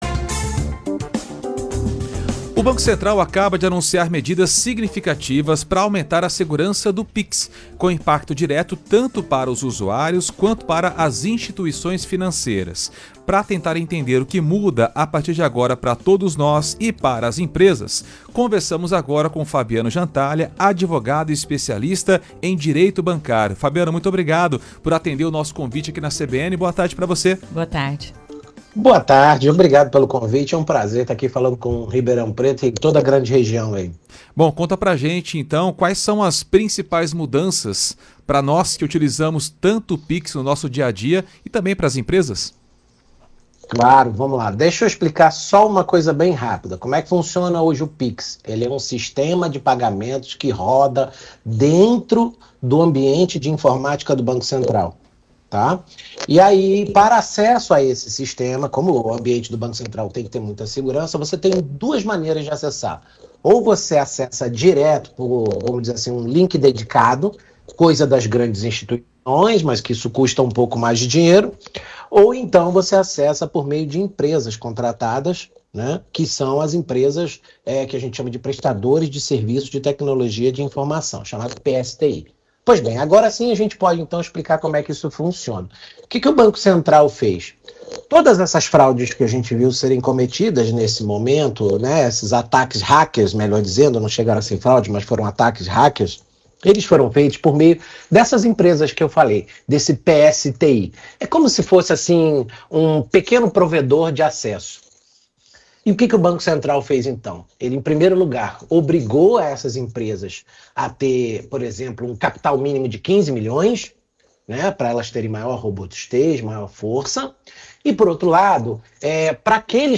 As novas regras do Pix anunciadas pelo Banco Central foram o tema da entrevista